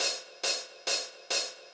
K-4 Hats Open.wav